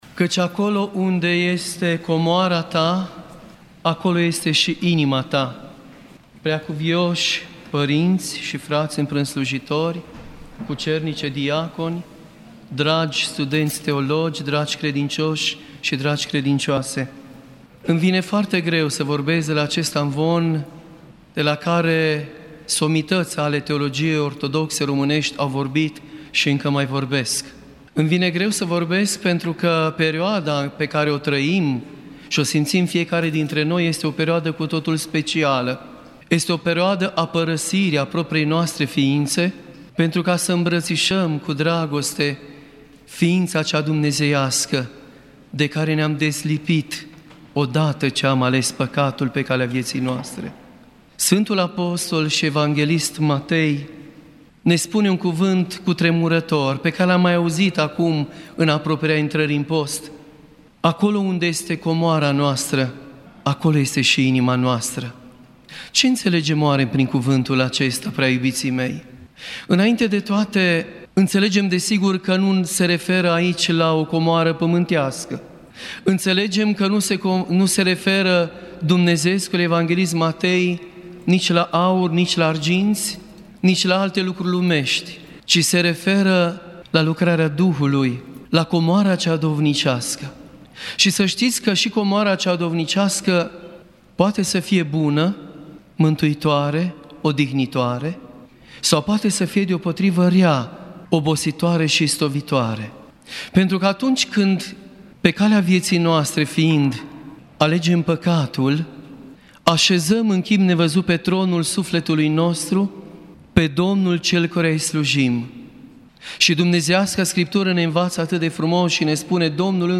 Predică la finalul Canonului cel Mare din Prima săptămână a Postului Mare